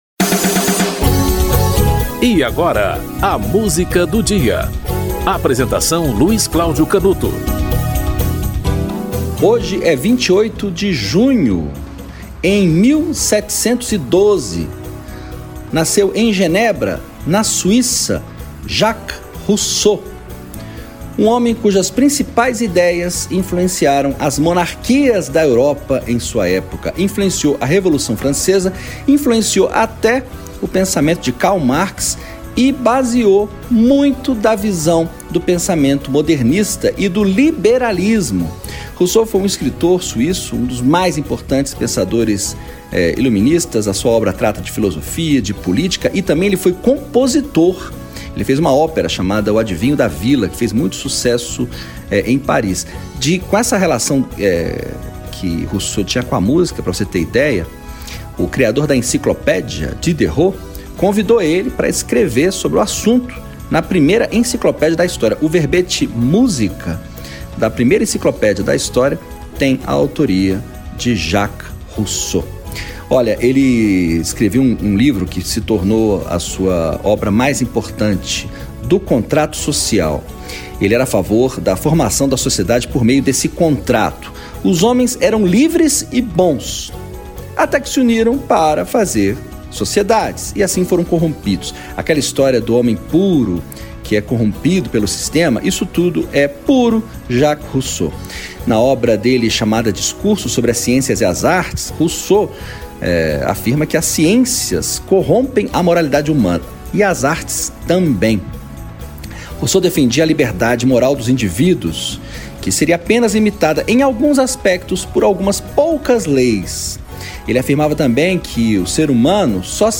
Raul Seixas - Ouro de Tolo (Raul Seixas)